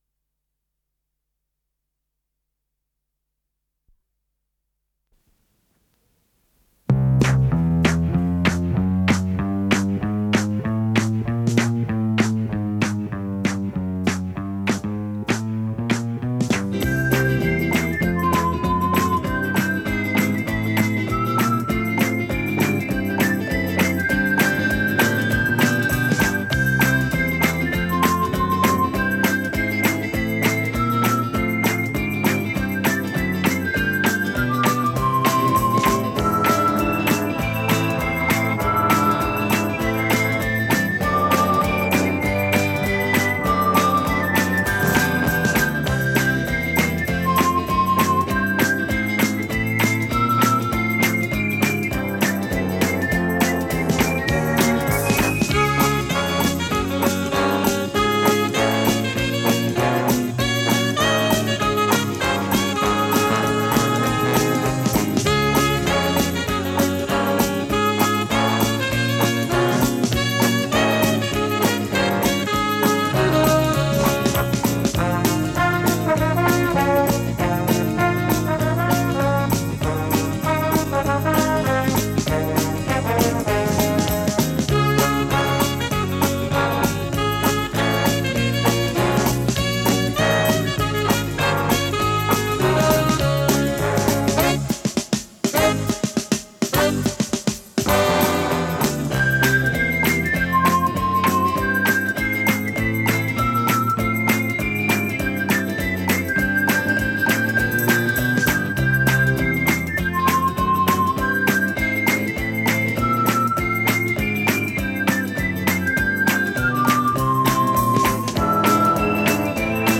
с профессиональной магнитной ленты
Тип лентыORWO Typ 106